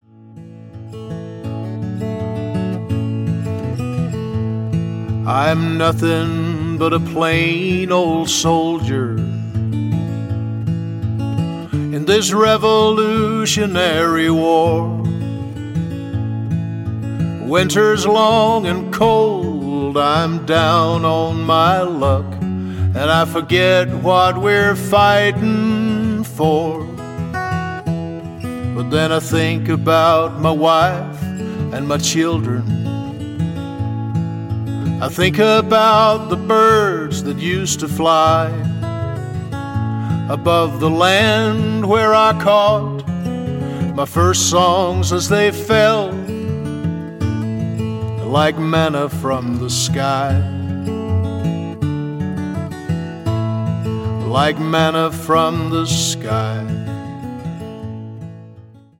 • Americana
• Country
• Folk
• Singer/songwriter